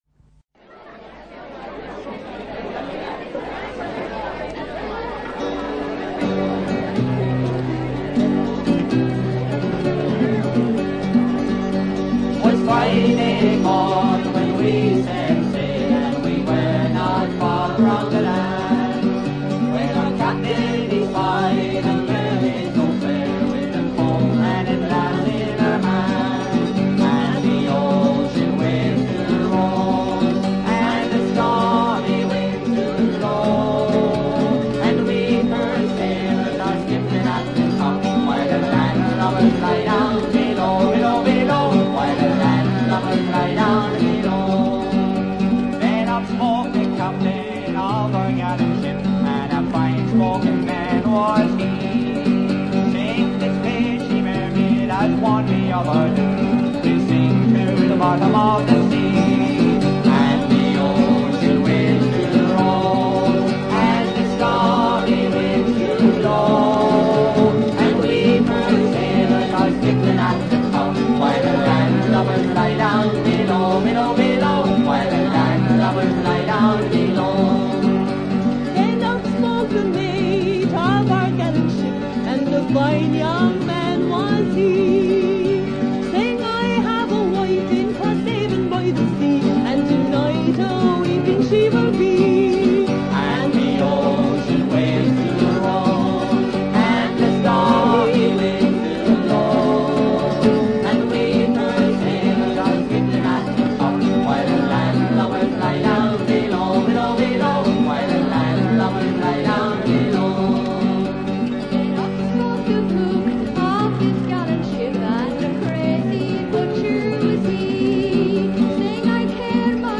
A native fishermen Ballad is performed with widespread instruments such as the Banjo and the Guitar: